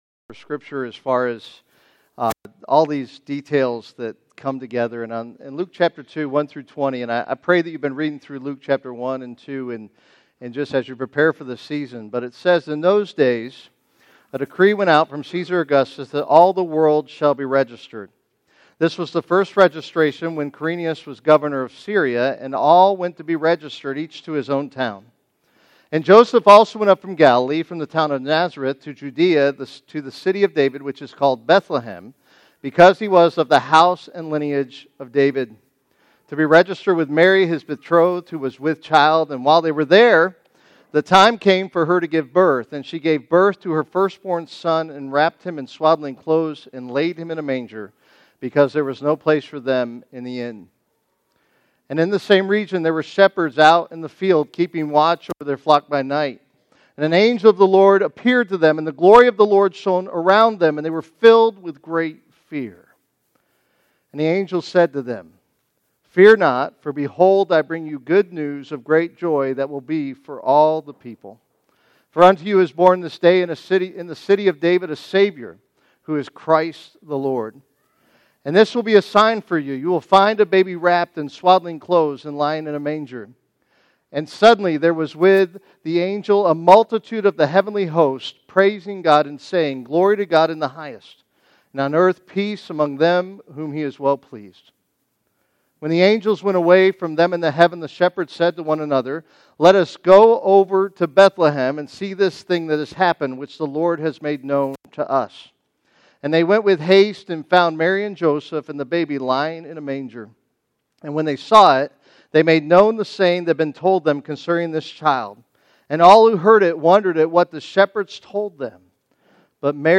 Christmas Eve Service – Promises Kept – First Baptist Church
Christmas-Eve-Service.mp3